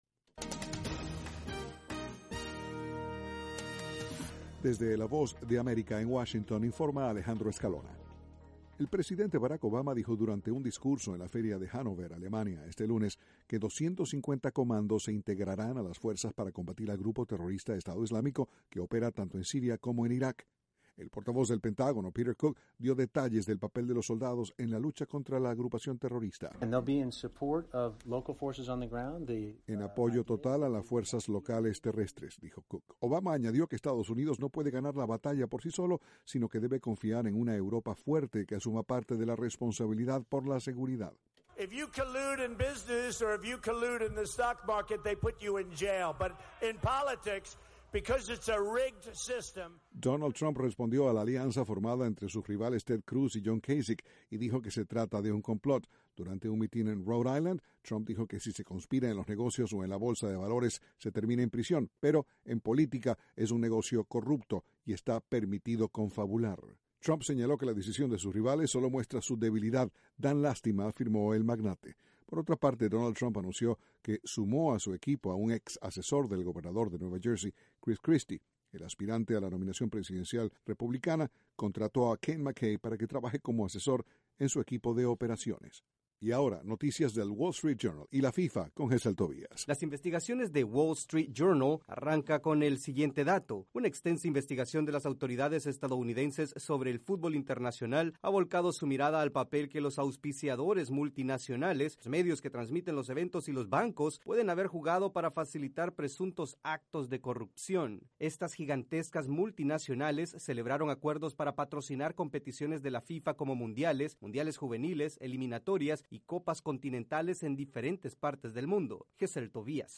VOA: Noticias de la Voz de América, Washington